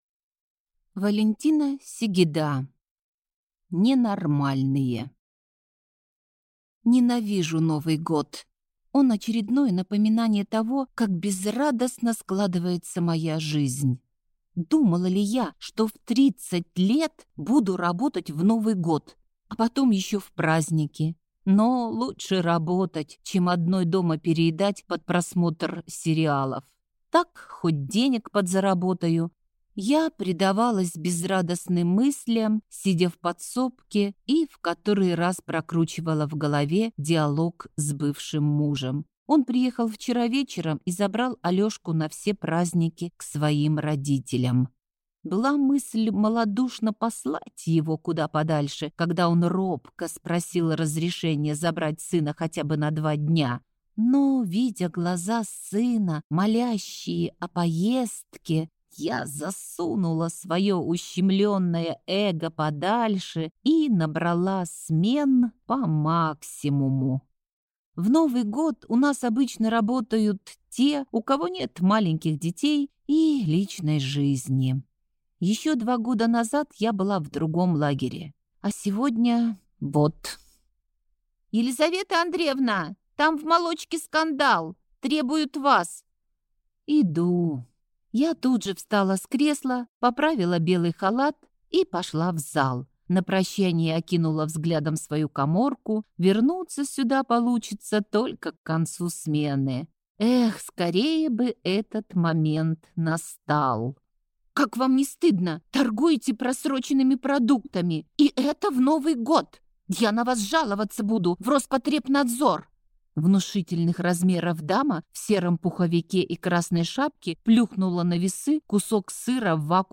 Аудиокнига Ненормальные | Библиотека аудиокниг